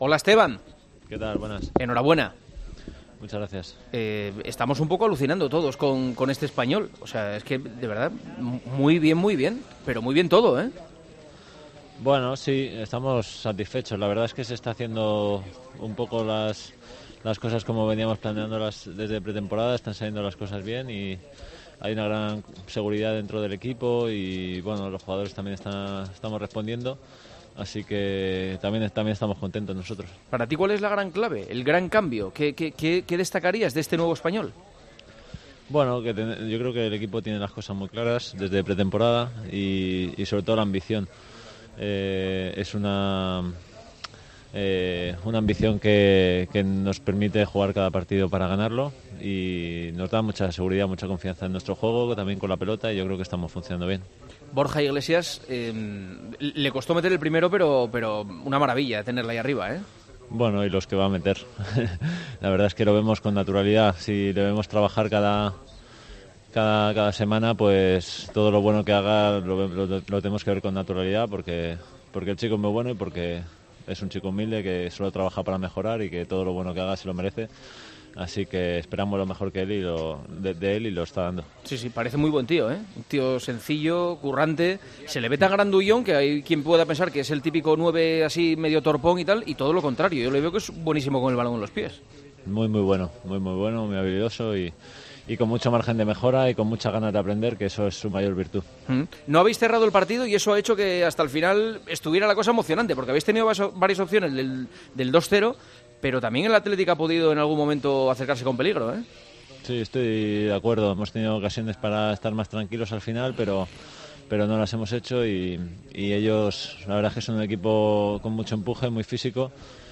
AUDIO: Hablamos con el jugador del Espanyol tras ganar al Athletic 1-0 y que sitúa a los 'pericos' segundos en la tabla a 3 puntos del Barcelona.